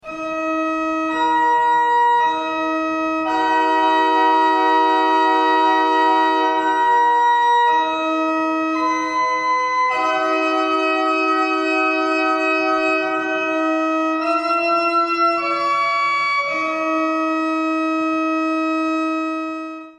Не исключено, что в «Тайной вечере» имеется ещё и четвёртый код, музыкальный: на фреске зашифрована мелодия духовного гимна.
hidden-music.mp3